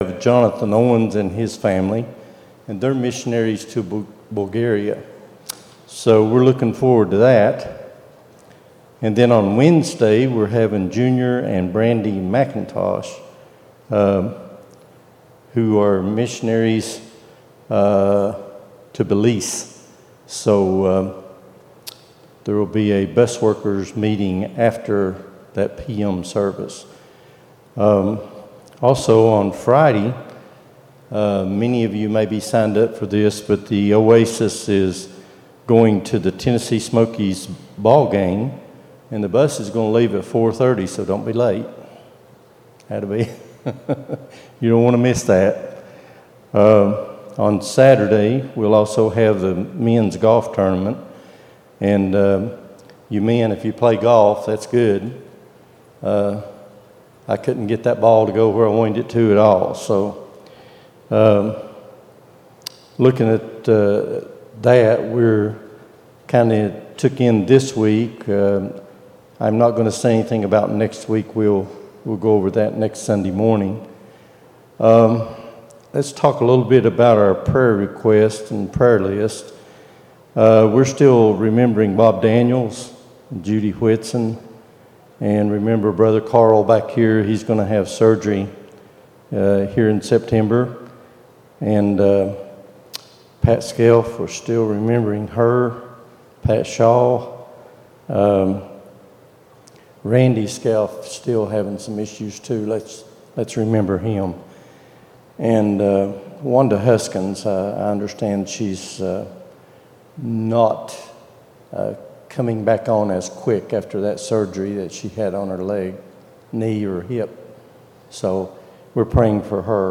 08-13-23 Sunday School | Buffalo Ridge Baptist Church